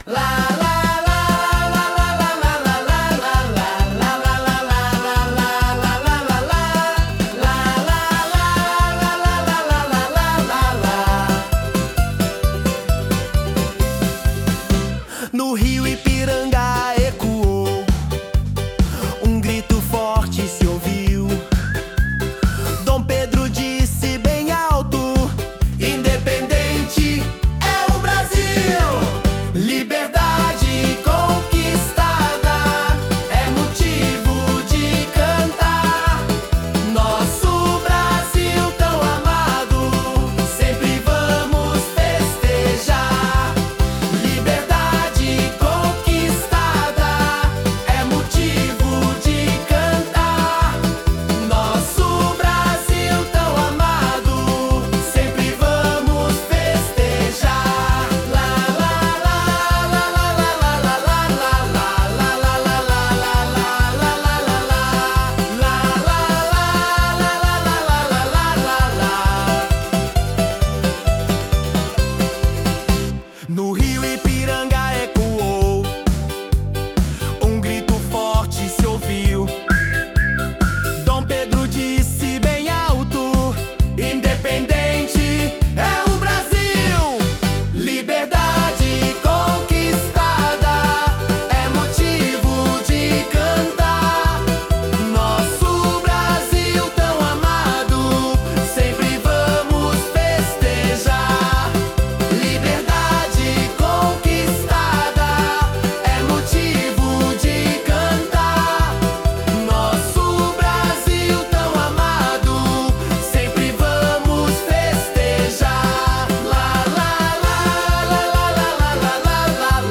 Letra da Música Infantil – Independência do Brasil